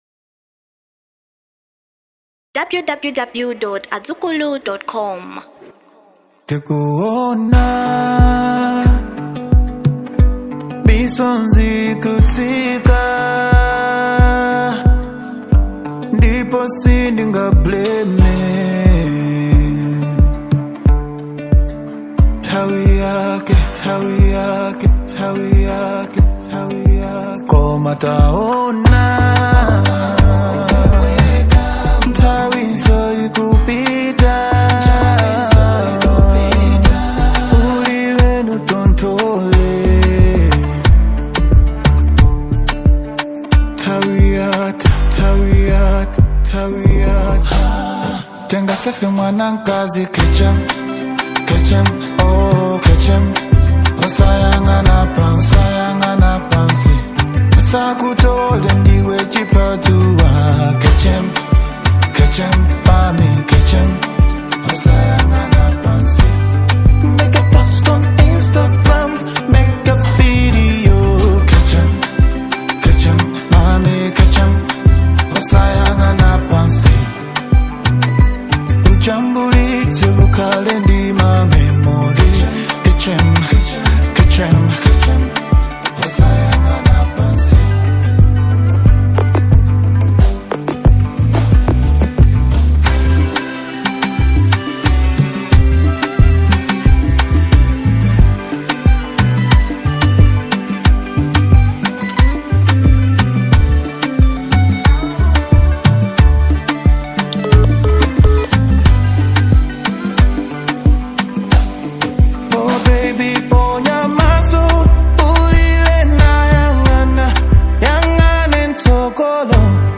Genre Afro Pop